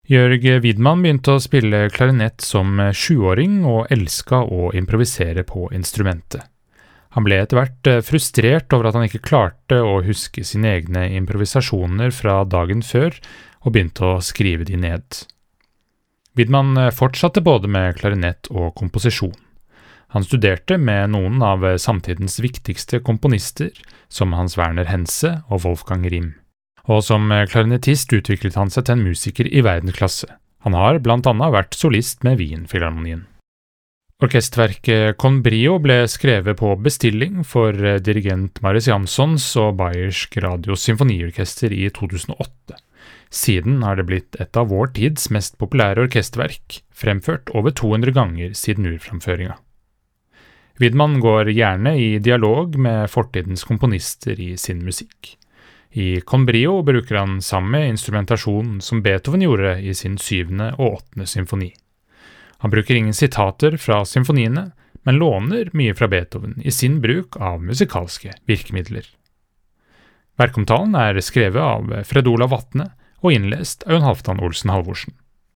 VERKOMTALE-Widmanns-Con-brio.mp3